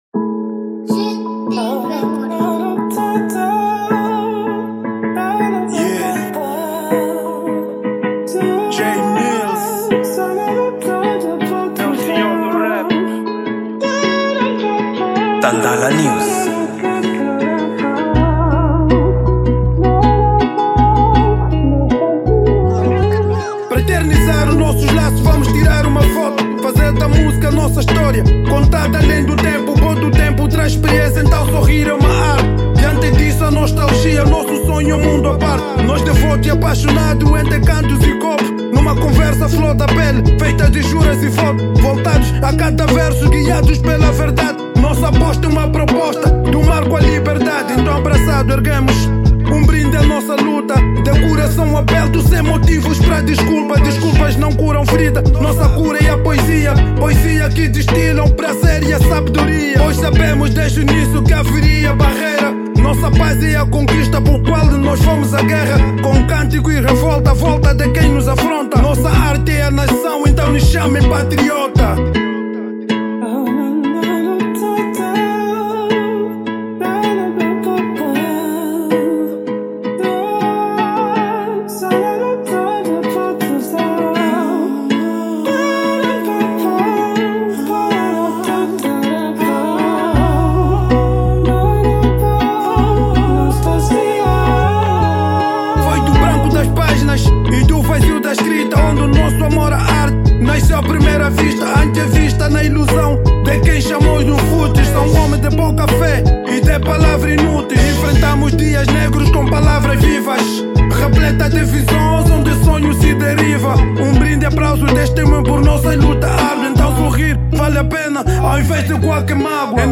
Gênero: Rap